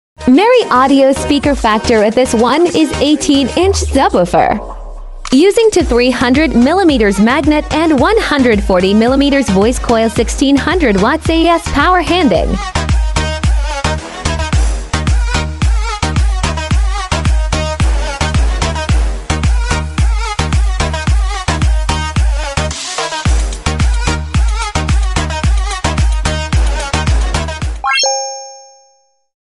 Professional speaker factory 18” subwoofer